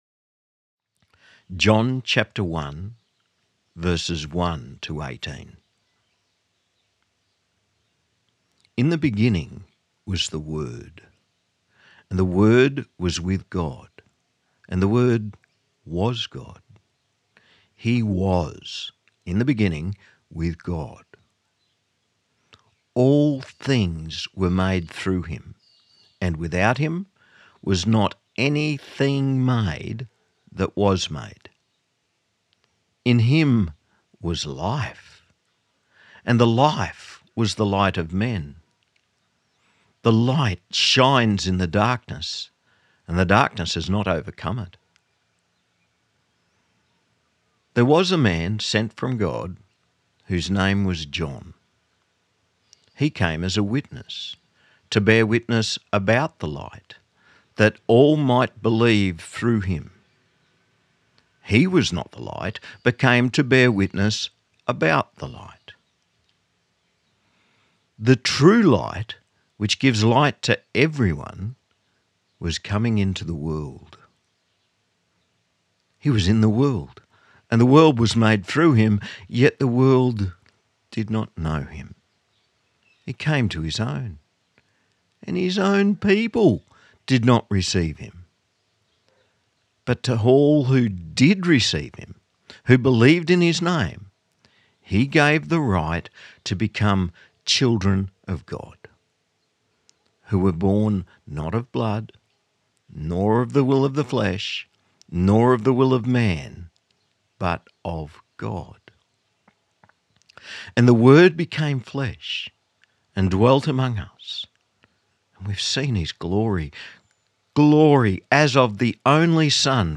1 Sermon 2025-12-21 Psalm 80 & Romans 1:1-7 Bondi Terror 31:17